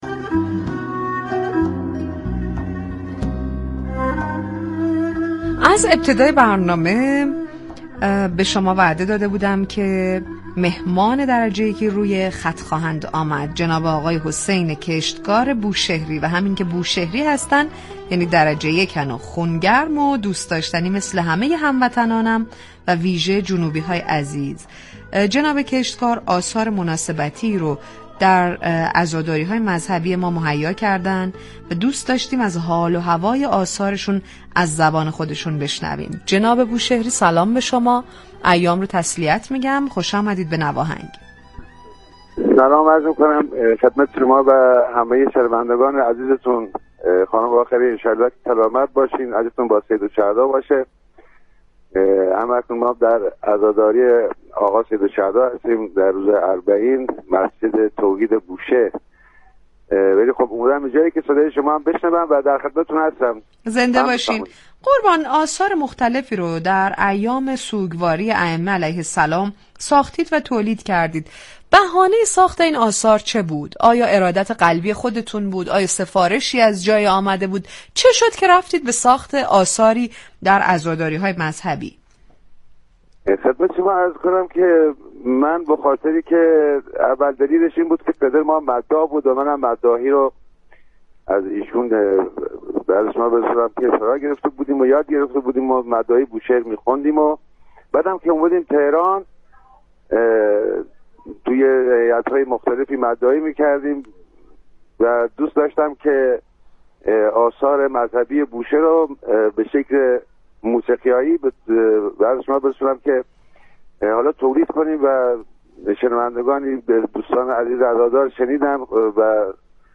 به گزارش روابط عمومی رادیو صبا،«نواهنگ» عنوان یكی از ویژه برنامه‌های موسیقی محور این شبكه رادیویی است كه در ایام مذهبی با پخش موسیقی و نواهنگ‌های مختلف همراه مخاطبان می‌شود.